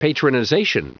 Prononciation du mot patronization en anglais (fichier audio)
Prononciation du mot : patronization